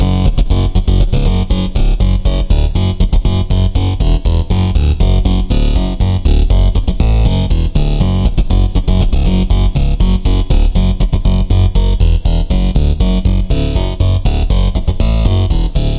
Tag: 120 bpm Electro Loops Synth Loops 2.69 MB wav Key : Unknown